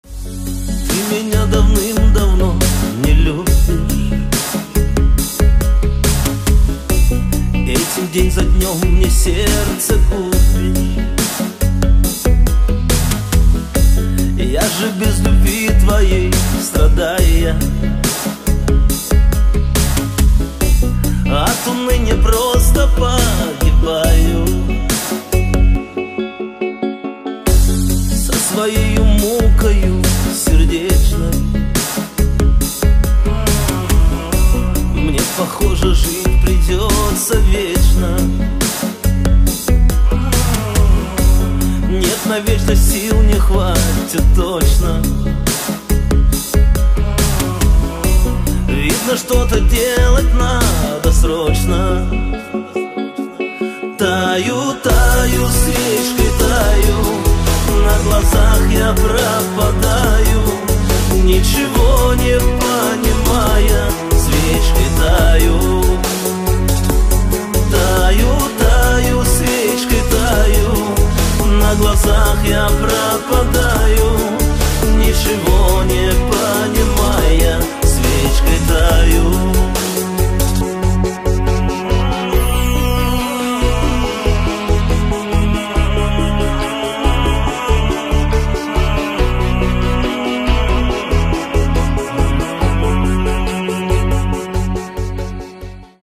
• Качество: 320, Stereo
гитара
мужской вокал
грустные
медленные
русский шансон